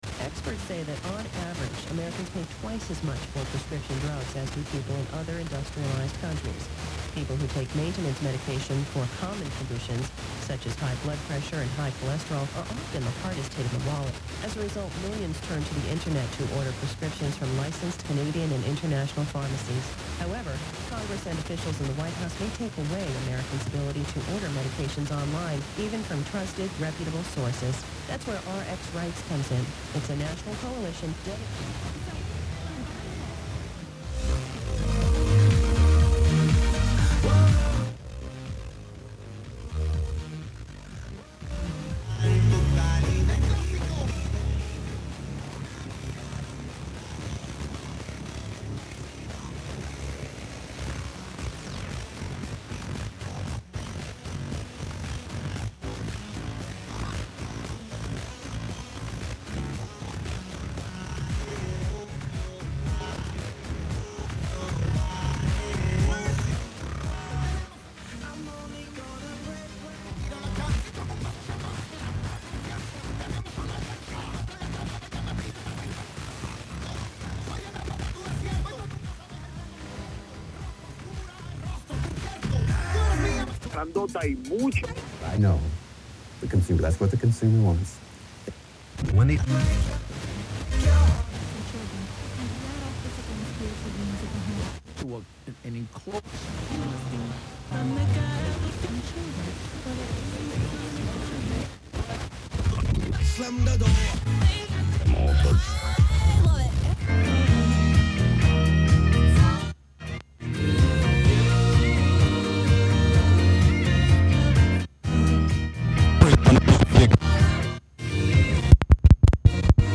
Transmission Arts & Experimental Sounds